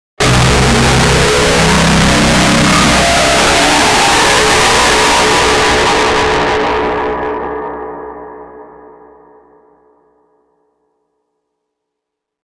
Loud Sudden BRAAH Sound Effect
Description: Loud sudden BRAAH sound effect. A sudden powerful impact sound, ideal for scary and intense cinematic scenes. Perfect for horror, thriller, jump scares, and dramatic moments in movies, video games, and trailers.
Loud-sudden-BRAAH-sound-effect.mp3